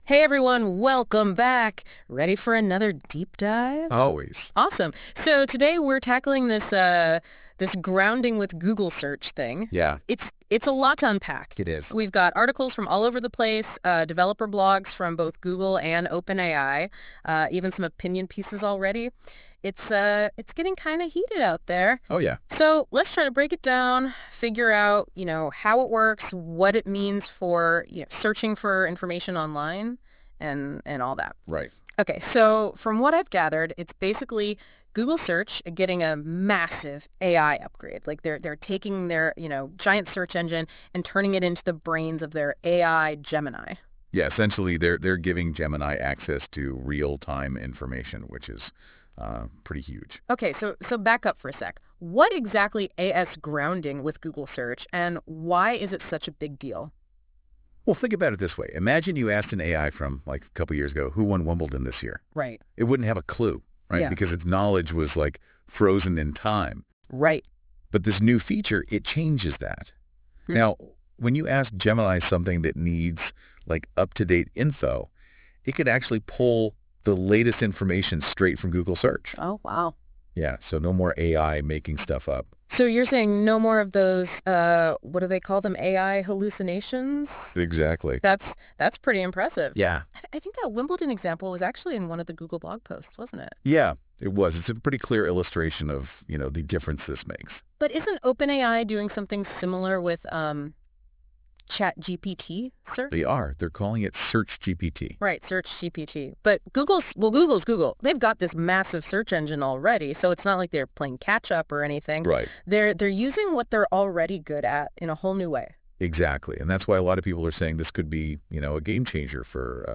Listen to the podcast version of this blog post, created with Notebook LM, for deeper insights and practical takeaways: